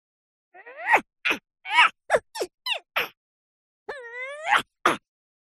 На этой странице собраны веселые и яркие звуки мультяшных драк: звонкие удары, шлепки, взвизгивания и другие забавные эффекты.
Крики во время драки